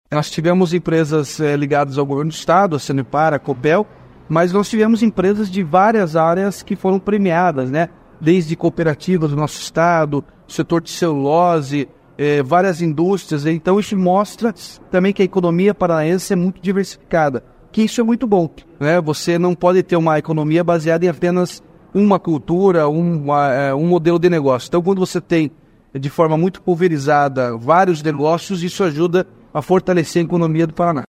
Sonora do governador Ratinho Junior sobre as empresas do Paraná estarem novamente no ranking anual das 500 Maiores Empresas do Sul do Brasil